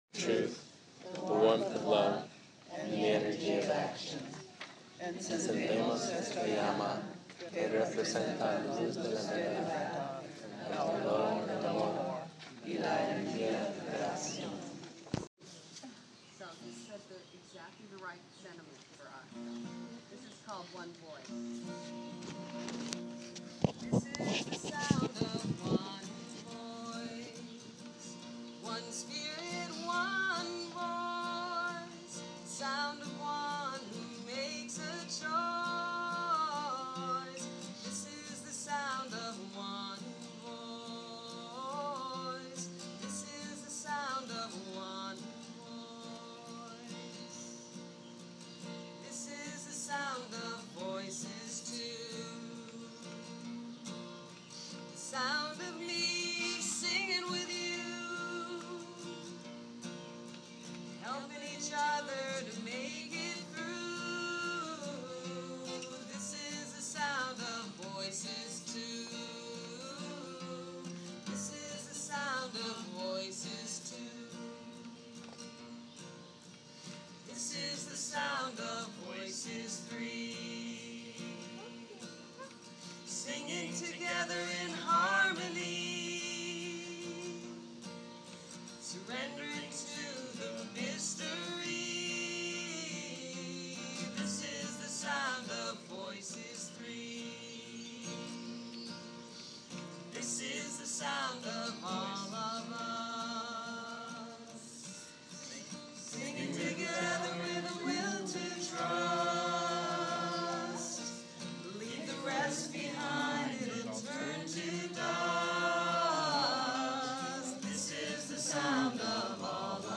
Sounds of Sunday Service, UUCiA Annual Retreat, Cape Cod Sea Camps.